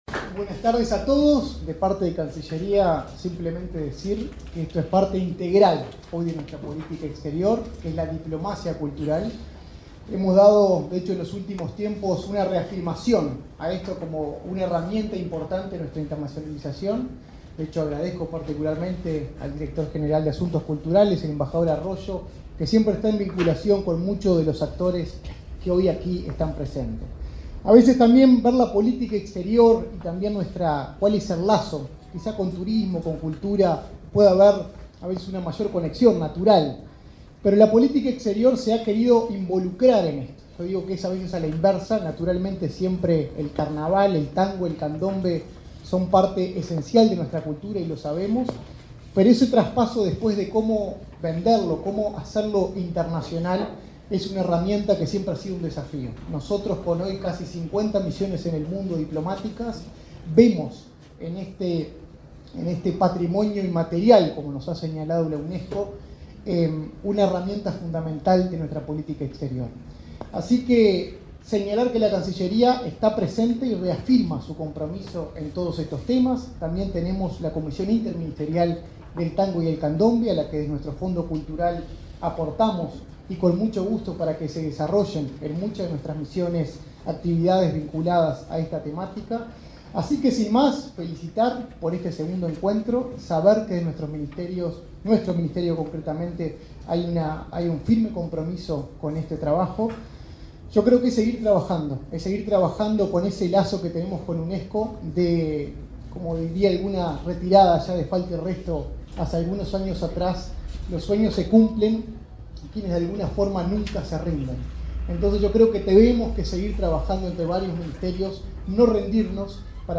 Palabra de autoridades en acto en Museo del Carnaval